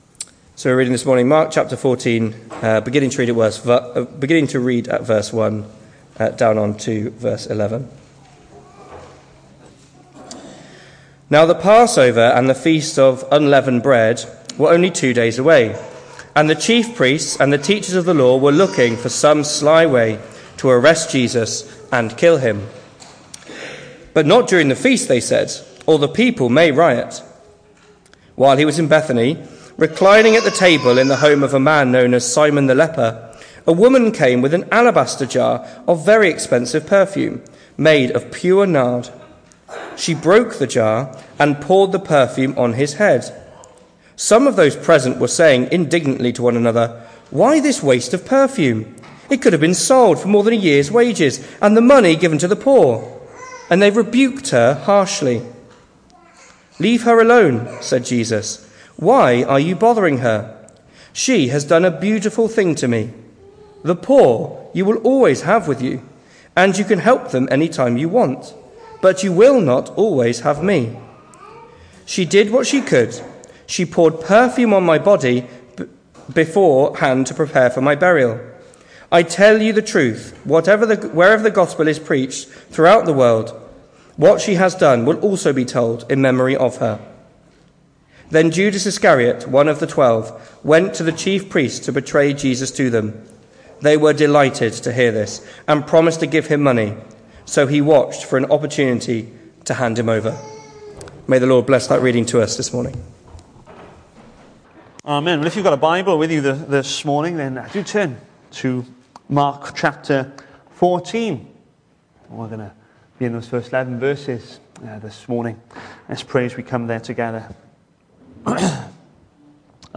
The 12th of October saw us host our Sunday morning service from the church building, with a livestream available via Facebook.